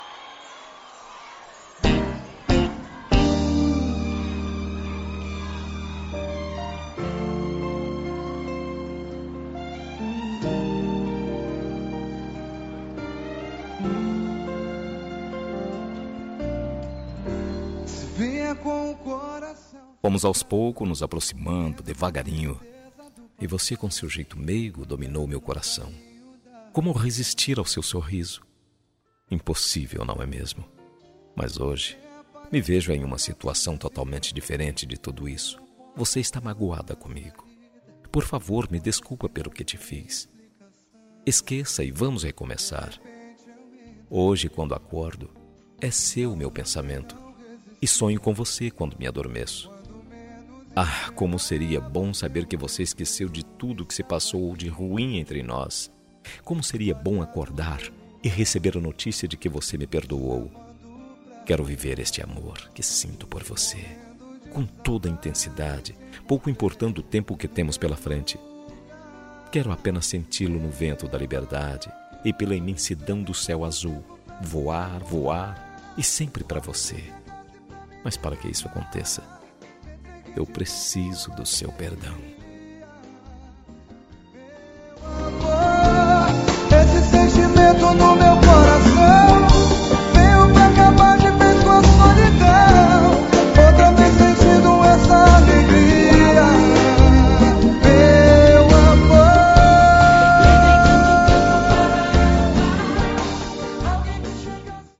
Telemensagem de Reconciliação – Voz Masculina – Cód: 7543